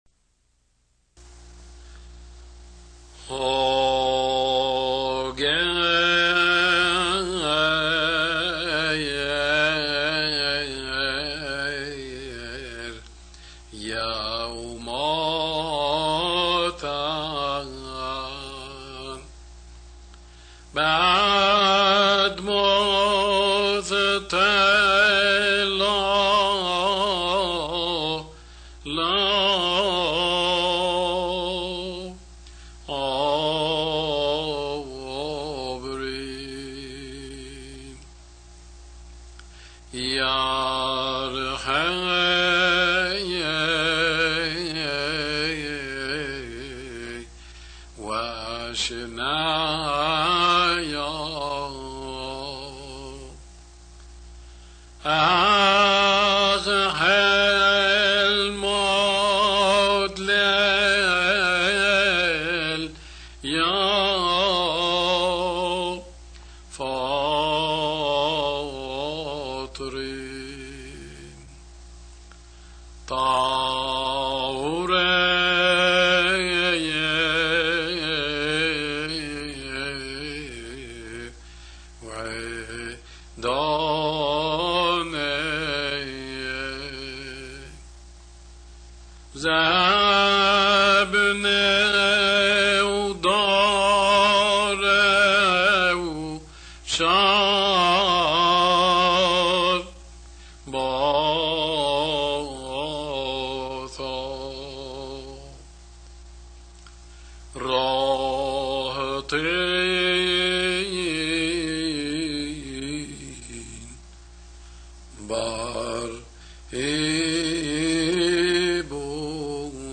Essa gravação foi, feita em fita magnética em 1961 aqui no Brasil e seu único intuito era o de ser um guia para estudos.
Músicas Sacras dos Primeiros Séculos do Cristianismo